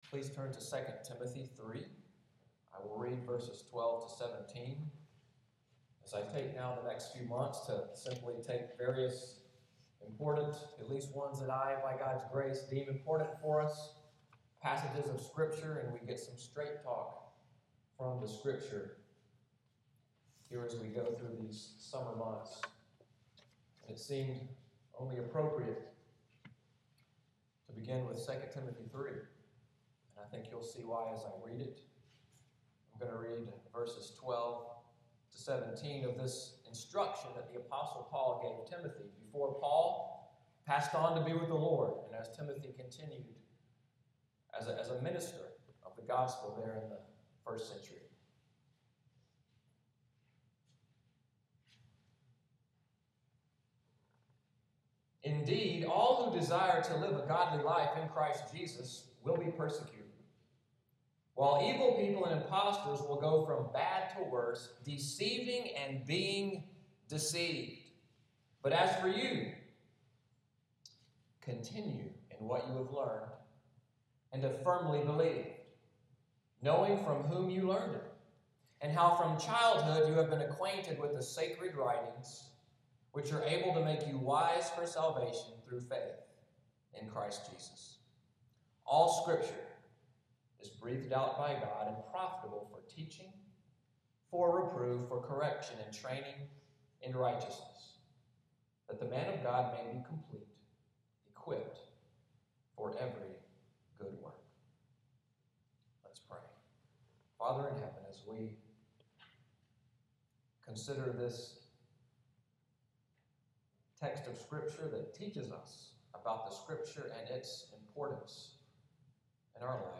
Audio from the sermon, “Thank God for the Bible!” June 8, 2014